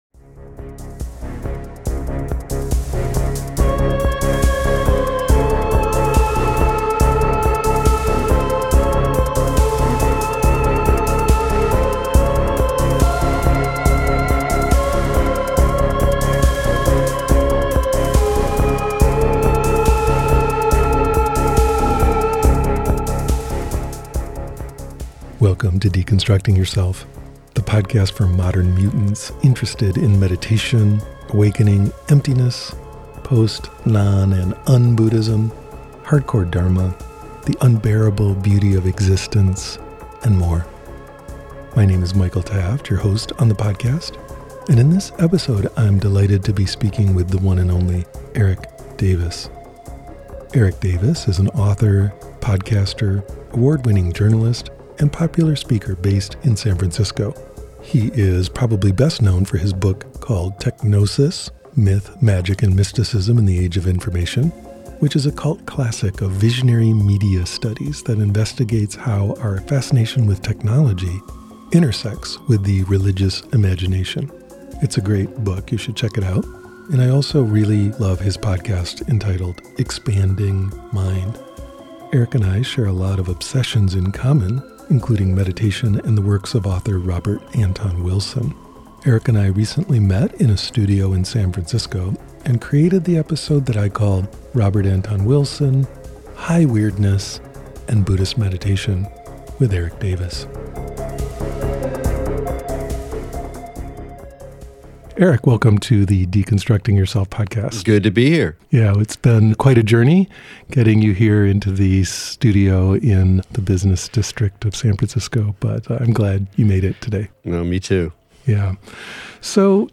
A podcast chat about High Weirdness, Zen, and ontological anarchism